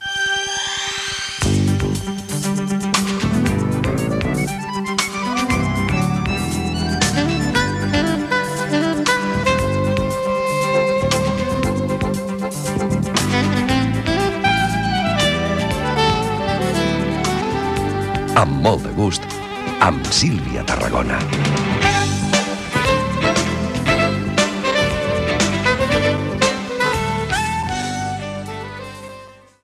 Careta d'entrada del programa.
Entreteniment